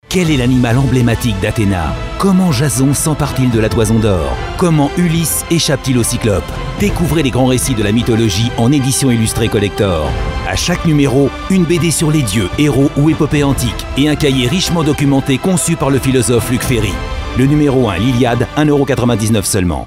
Un timbre de voix allant du médium au grave, Une voix élégante et chaleureuse, avec une large palette de styles d'interprétation et de tons.
Une voix off pleine d’emphase qui vous présente cette nouvelle collection !